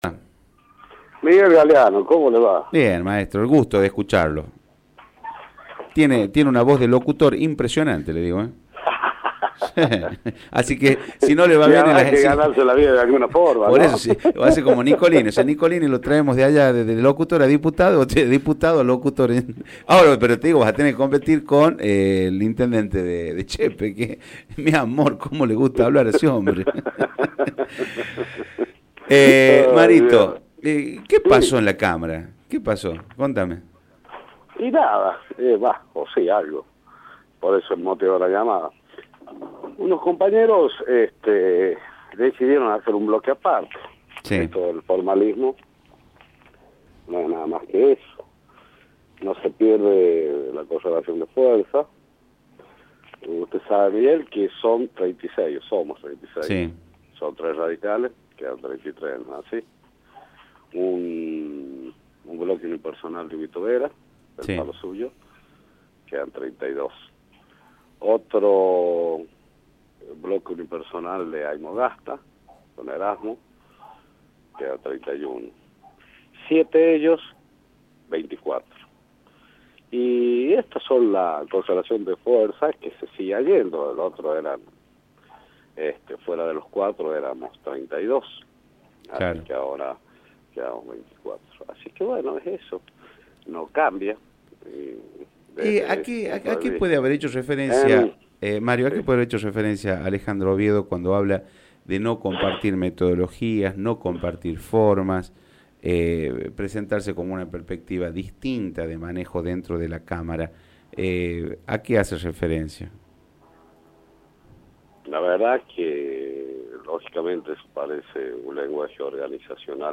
Guzmán Soria lo planteó, a través de Radio Rioja, luego de la ruptura del bloque oficialista, en donde un grupo de legisladores liderados por la chepeña Alejandra Oviedo conformó el bloque “Caudillos Federales”.